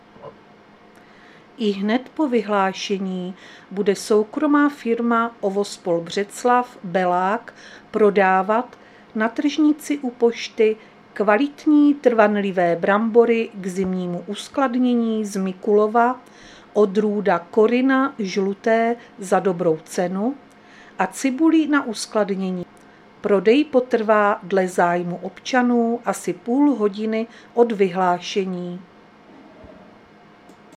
Záznam hlášení místního rozhlasu 27.8.2025
Zařazení: Rozhlas